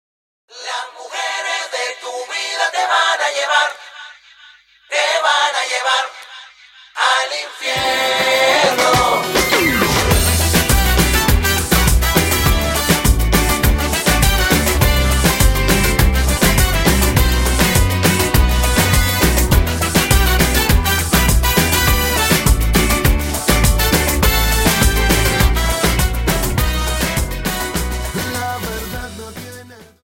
Dance: Samba Song